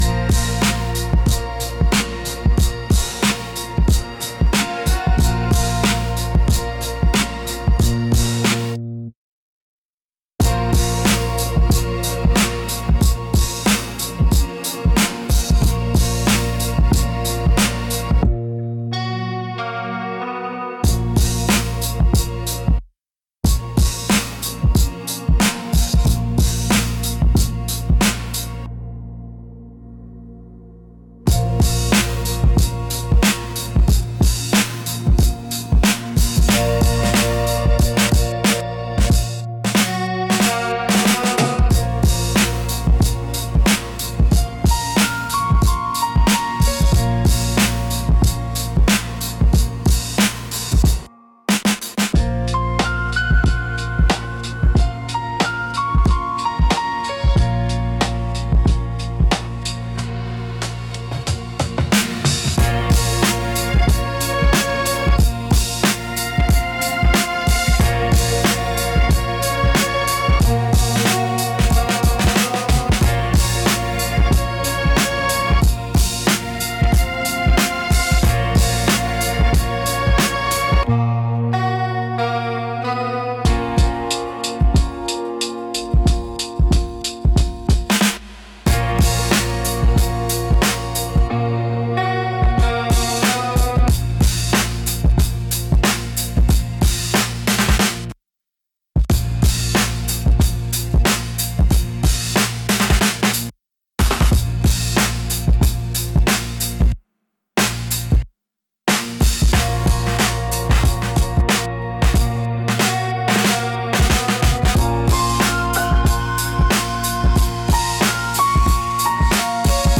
Instrumental - The Way You Tease - 2.30